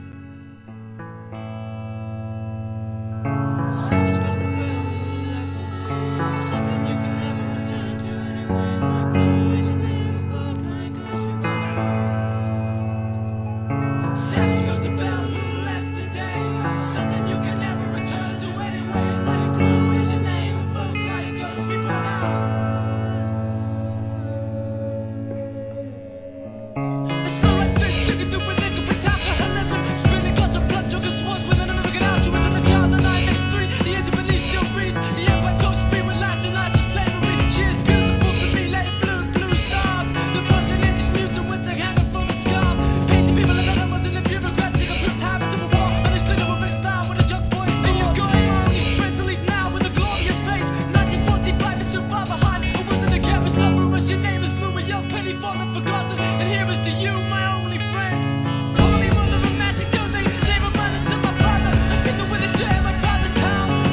1. HIP HOP/R&B
哀愁アブストラクトの天才ビートメイカー!!